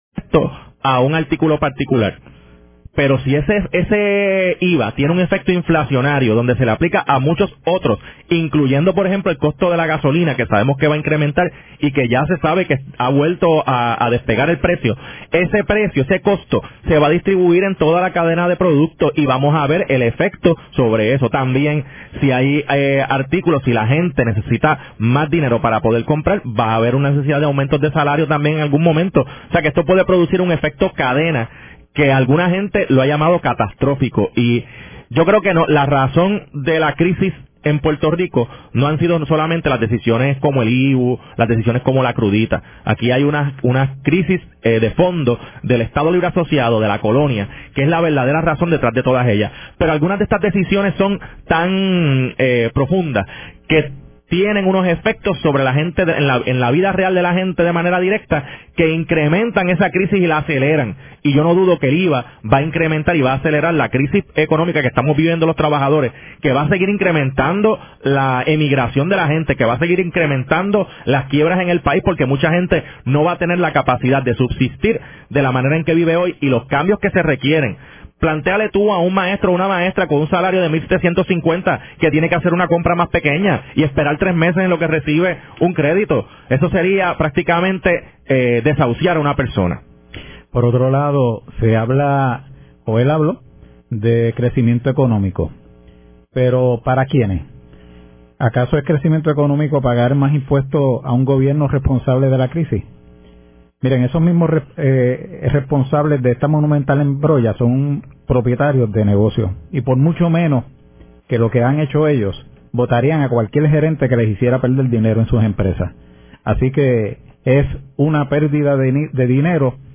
Emisora: 740 AM